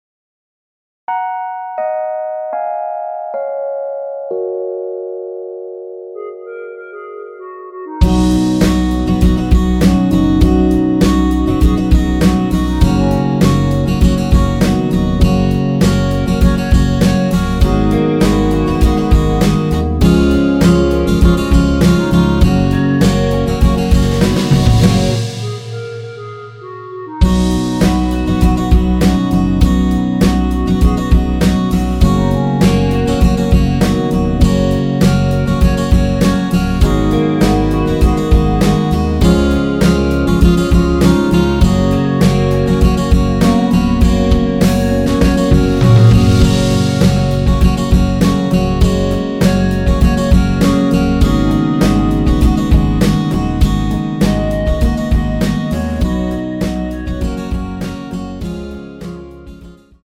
원키에서(-2)내린 멜로디 포함된 MR입니다.
F#
앞부분30초, 뒷부분30초씩 편집해서 올려 드리고 있습니다.
중간에 음이 끈어지고 다시 나오는 이유는